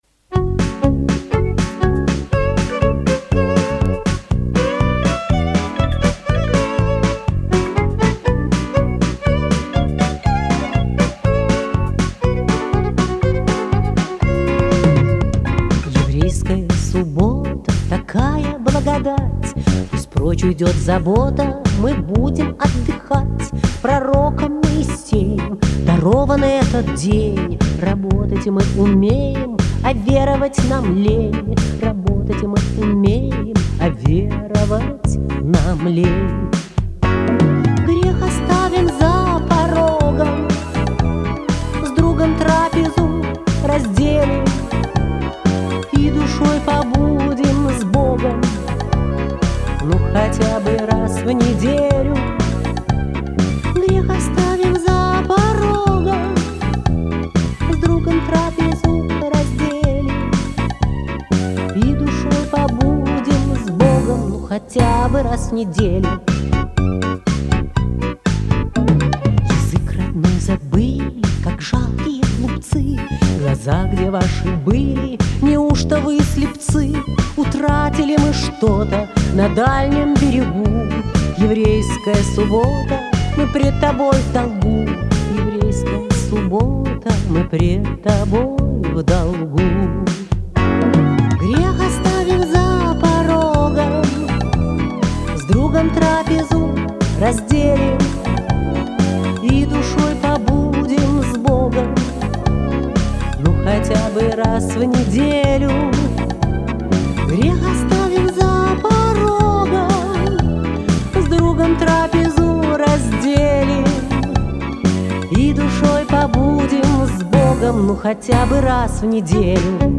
рестор.муз. (закрыта)